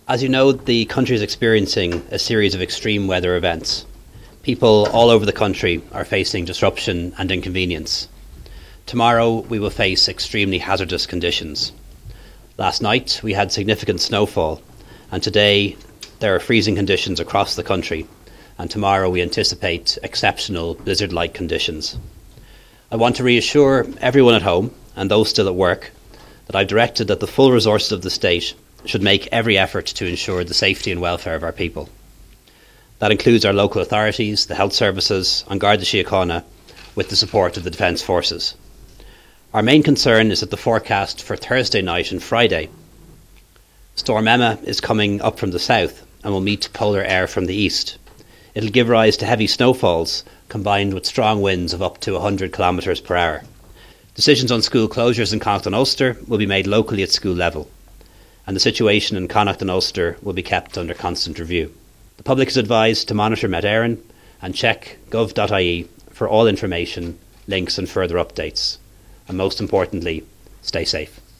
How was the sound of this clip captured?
Speaking at the Governments Emergency Coordination Committee headquarters this evening he said the full resources of the state are being mobilised and decisions on school closures in Ulster will be made locally: